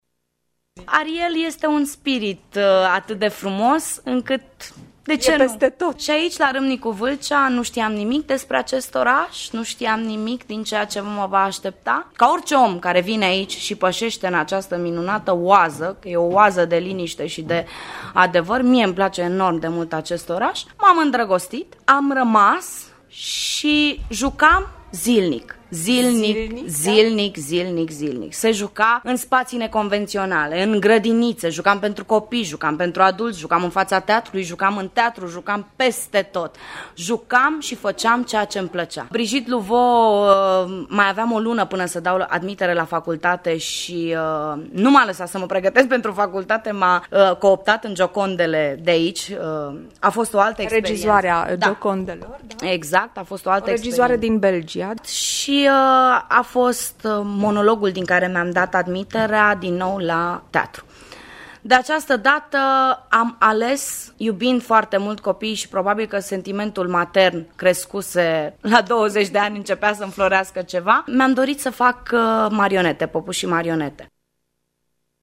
de vorbă cu actriţa